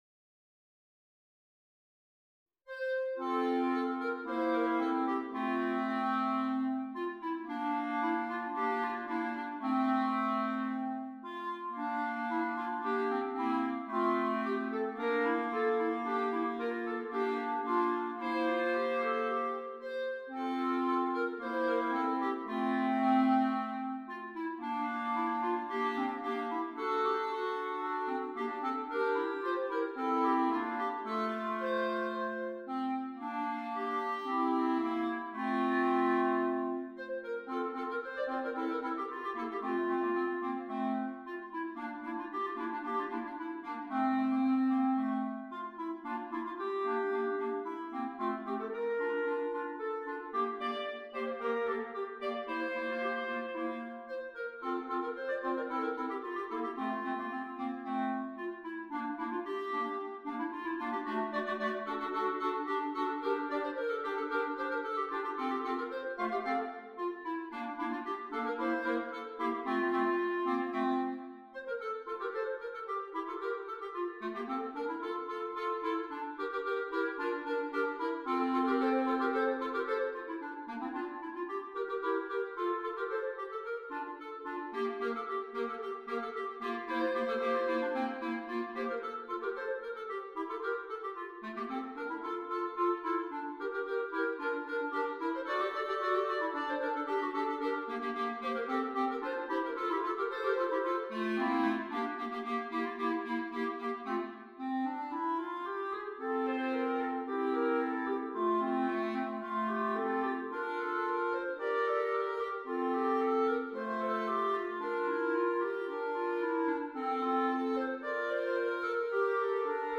3 Clarinets